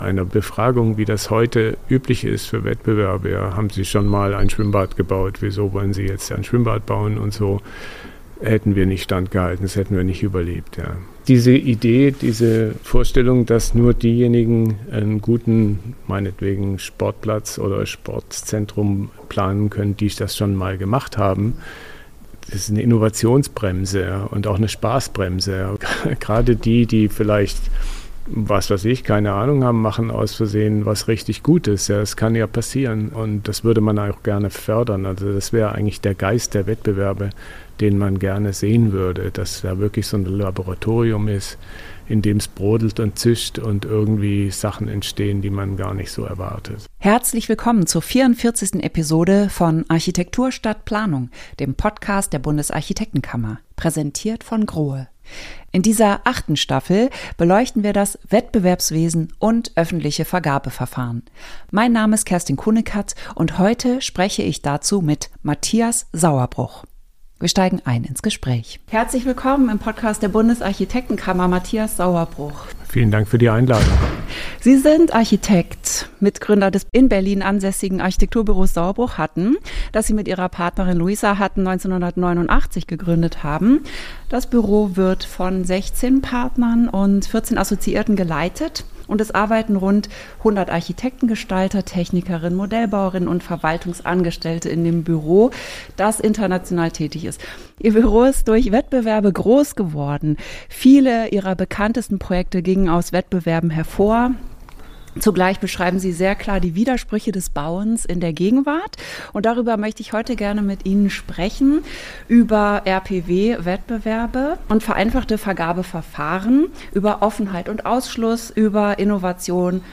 Heute vermisst er Verfahren, die Risikobereitschaft und Erfindergeist belohnen. Ein Gespräch über Aufbruch, Zugangsbedingungen und die Frage, welche Architektur entsteht, wenn nur noch die Erfahrenen zugelassen werden und Innovation nicht mehr gefördert wird.